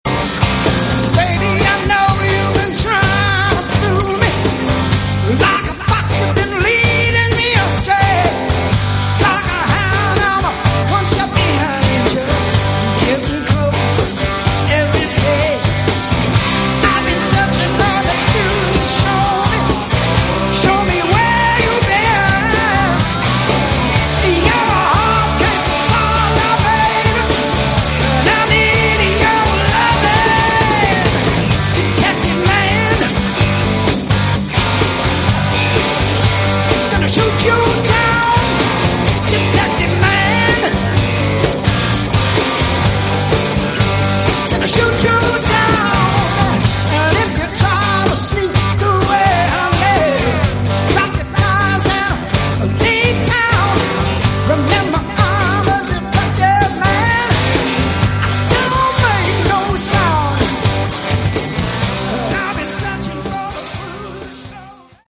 Category: Hard Rock
vocals, guitar
drums
bass
lead guitar